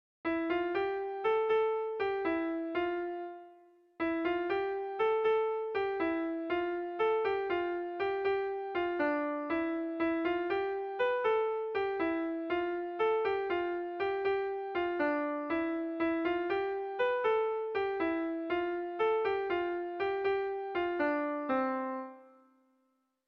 Dantzakoa
Aurreko doinuaren erritmoa zertxobait aldatuz baina doinu bera noski.
Kopla handiaren moldekoa